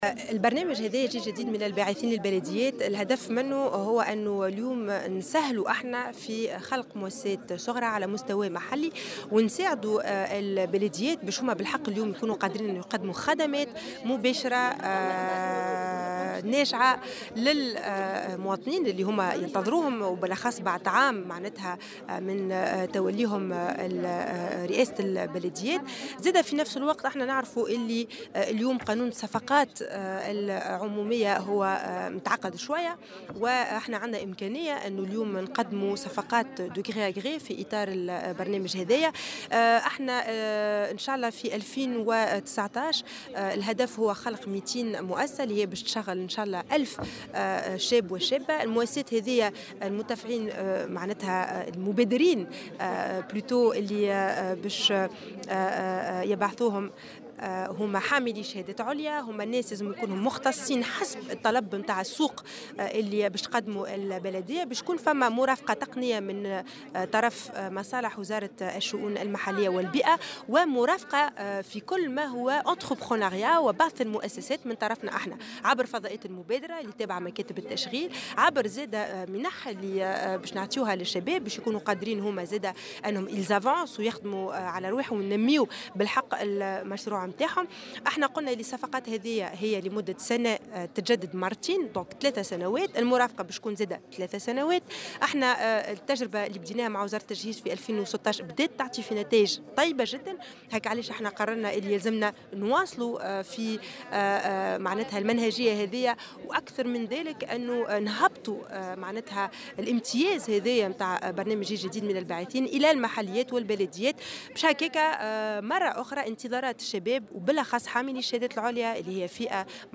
وأوضحت وزيرة التشغيل، سيدة الونيسي في تصريح لمراسل "الجوهرة أف أم" أن البرنامج يهم أصحاب الشهادات العليا المعطلين عن العمل والراغبين في إنشاء مؤسسات صغرى في مجال الخدمات البيئية.